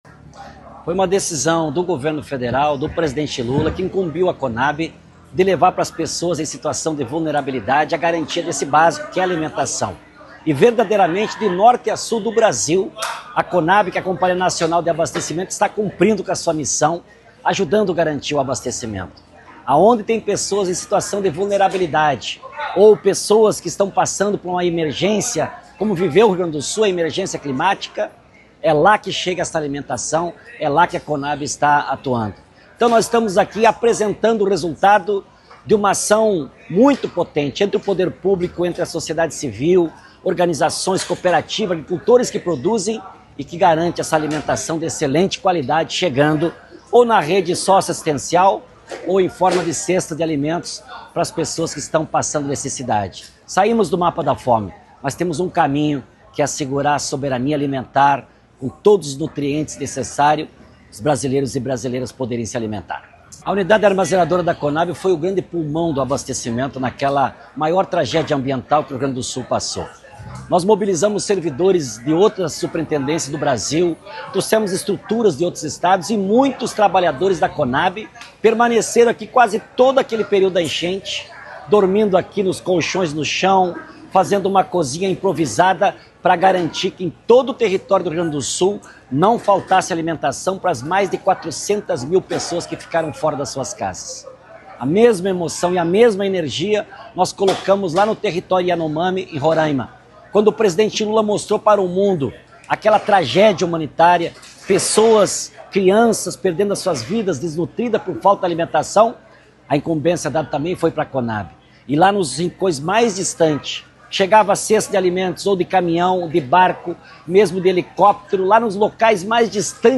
Evento aconteceu na Unidade Armazenadora da Companhia em Canoas, que foi o centro de distribuição de 153 mil cestas de alimentos nas enchentes de 2024
Cestas-Edegar-Pretto-presidente-da-Conab.mp3